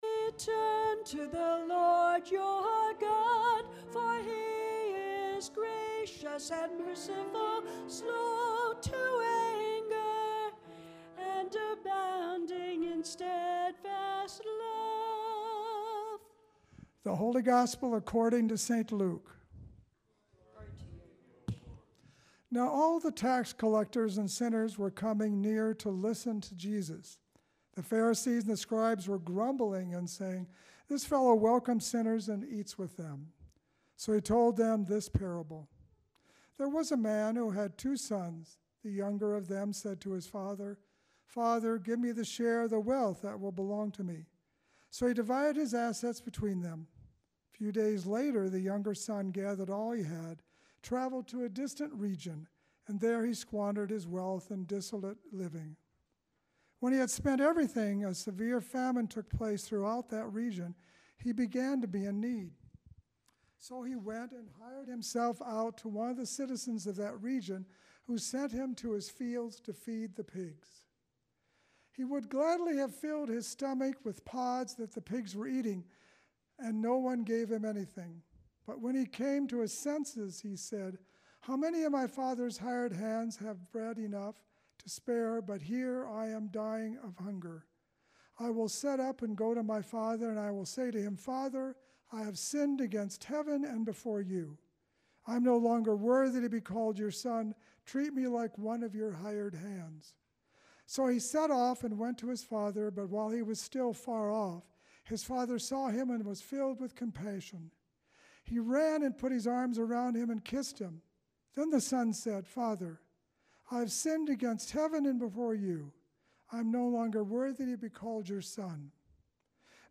Sermon 03.30.25